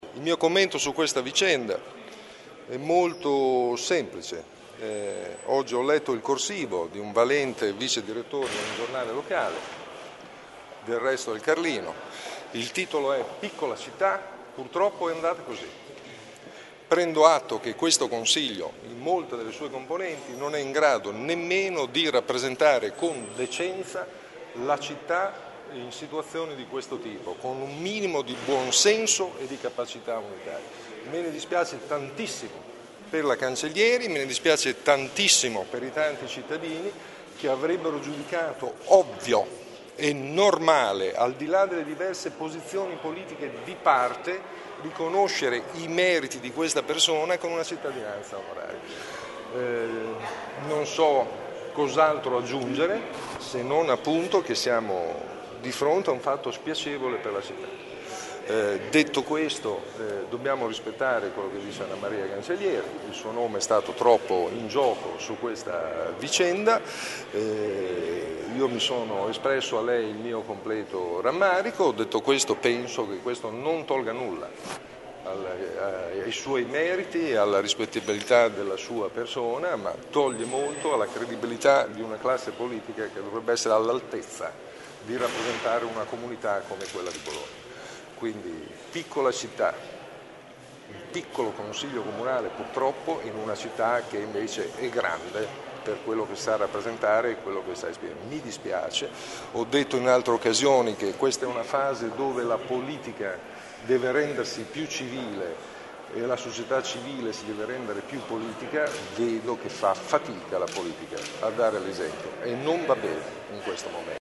Ascolta il sindaco: